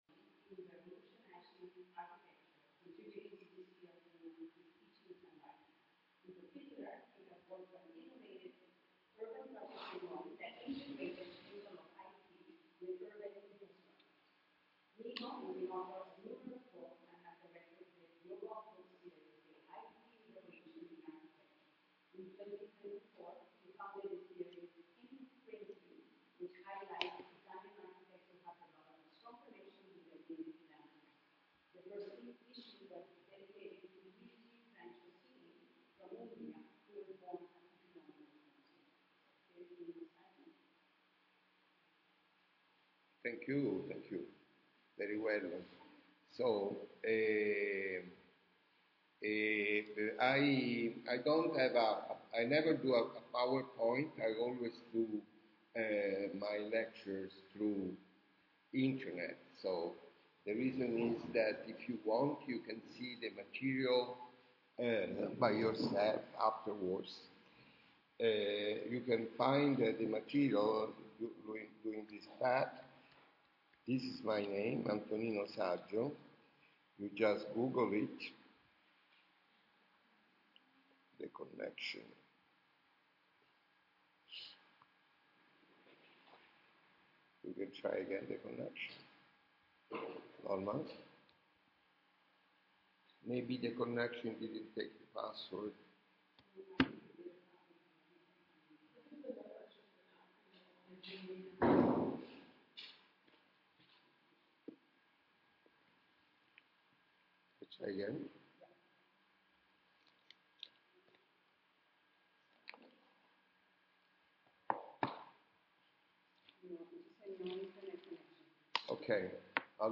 lectio